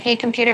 synthetic-wakewords
ovos-tts-plugin-deepponies_Kim Kardashian_en.wav